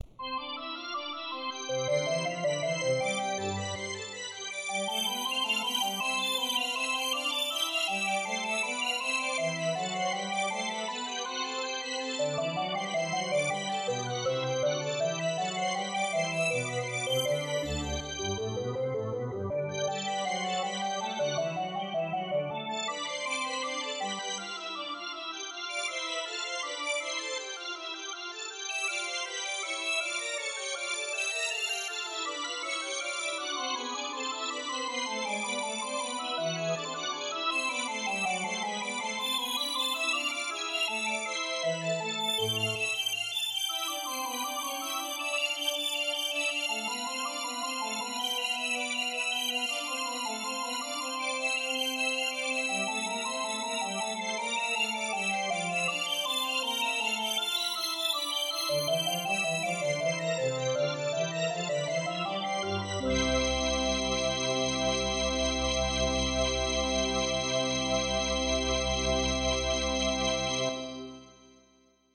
Je viens de réussir à faire jouer un fichier MIDI avec le B6 fourni en guise d'exemple avec CsoundQt.
Cet instrument est contrôlé par 76 paramètres (2x9 drawbars, scanner, distorsion, delay, reverb, leslie, etc), ce qui explique - avec la présentation aérée du code - le nombre de lignes importantes.